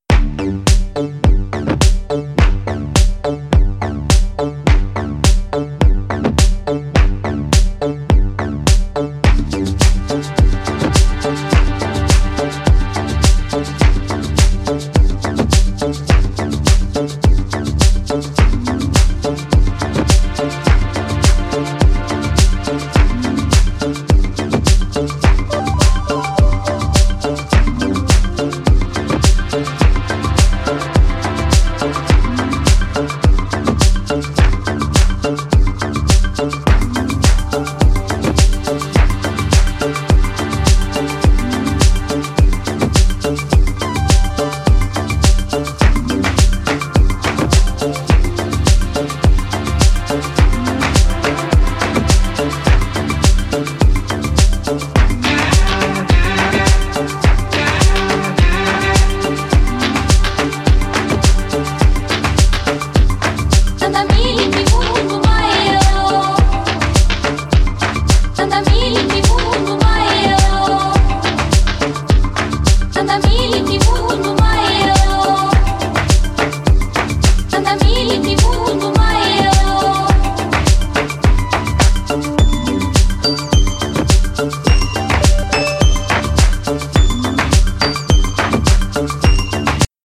個性的なスロー・トライバル〜ワールド・ミュージックっぽいオリジナルをスタイリッシュにクラブ・トラック化！